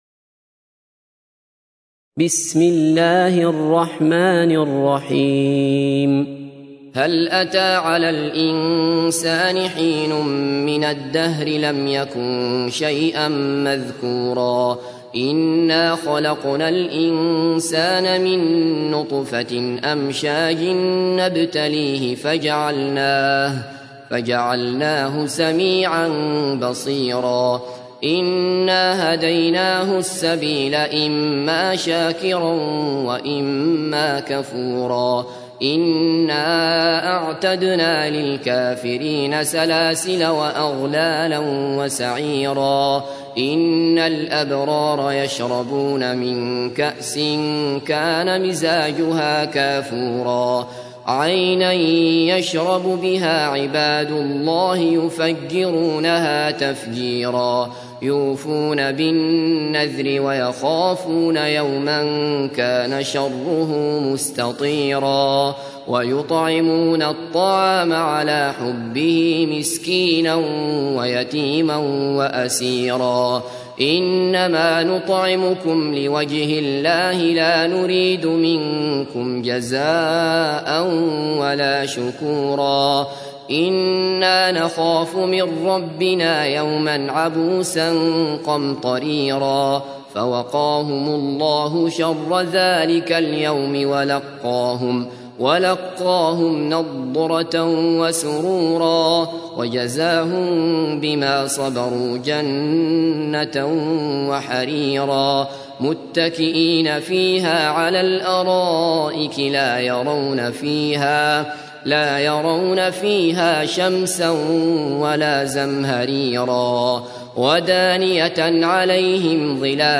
تحميل : 76. سورة الإنسان / القارئ عبد الله بصفر / القرآن الكريم / موقع يا حسين